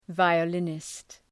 {,vaıə’lınıst} (Ουσιαστικό) ● βιολιστής